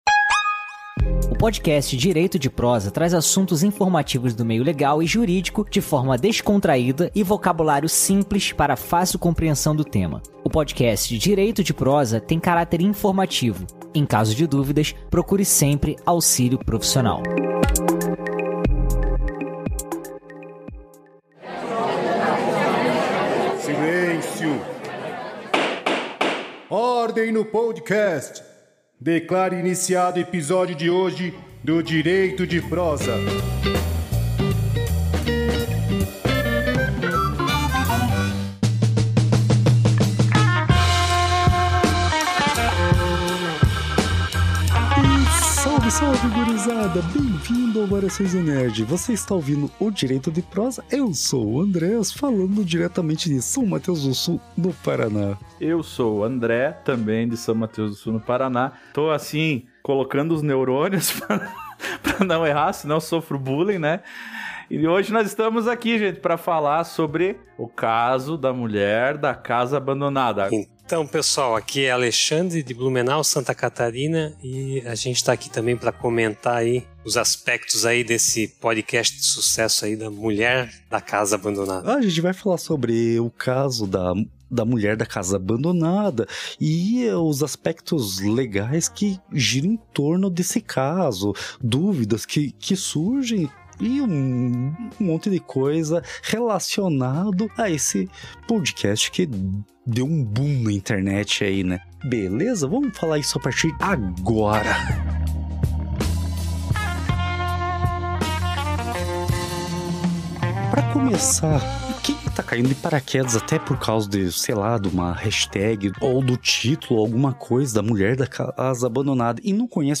O podcast “Direito de Prosa” é um dos programas da família Variações de um Nerd, onde tratamos de assuntos e temas do meio jurídico de forma leve e linguagem mais simples, e sempre com especialistas para trazer propriedade e veracidade ao conteúdo.